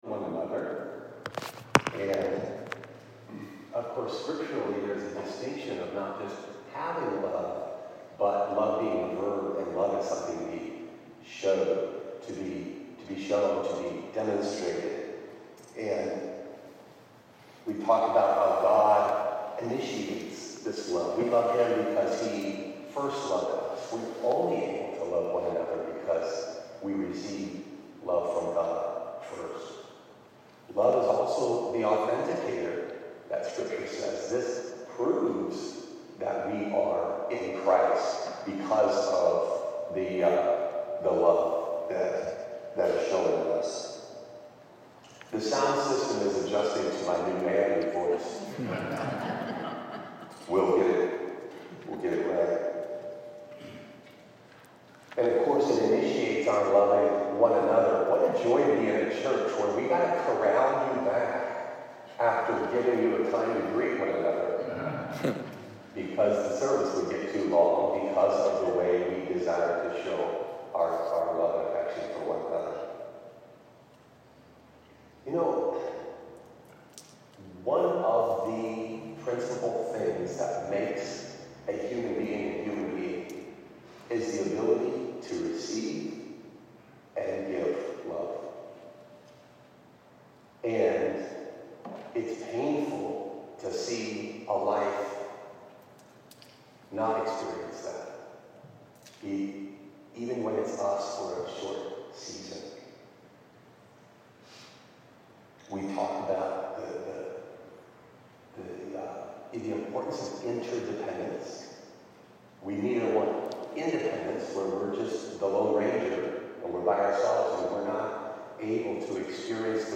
Sermon-5-Oct-25.mp3